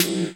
Snare - Roland TR 45